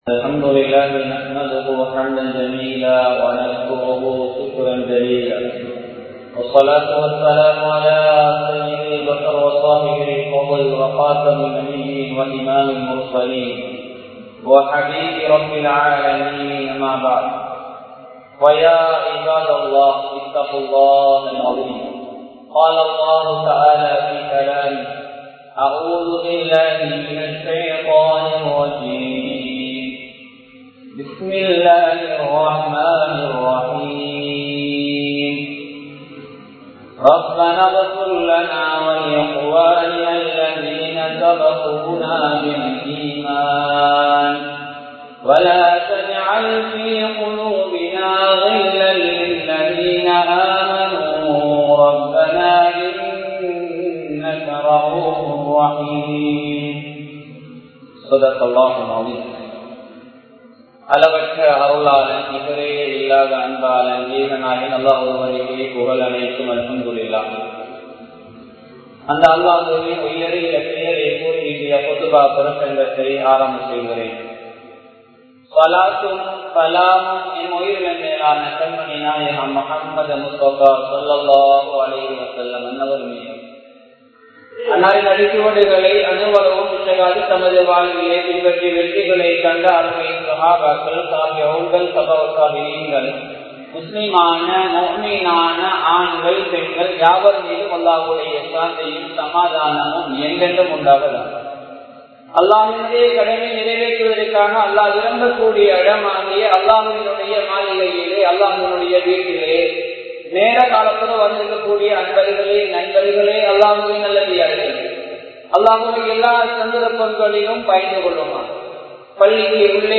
நன்மைகளை அழிக்கும் பொறாமை | Audio Bayans | All Ceylon Muslim Youth Community | Addalaichenai
Kurunegala, Nikaweratiya, Ameeniyya Jumua Masjidh